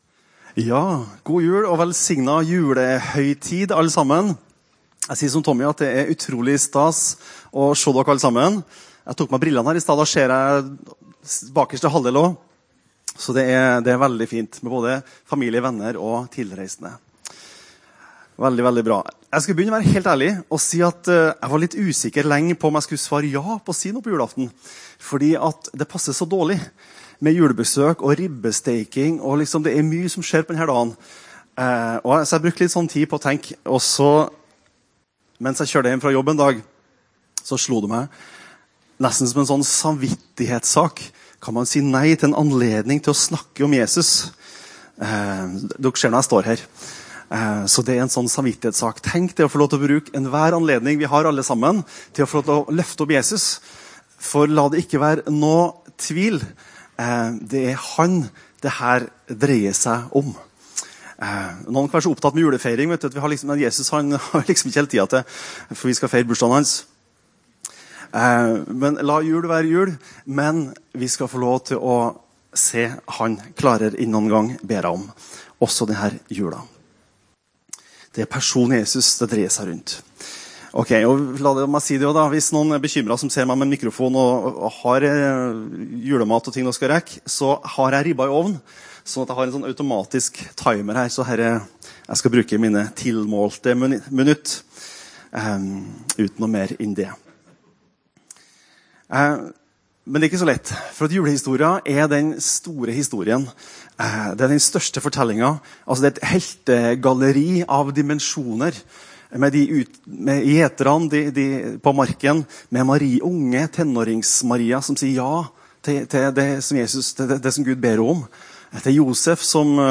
Tid: 24. des. 2025 kl. 14 Sted: No12, Håkon den godes g. 12, Levanger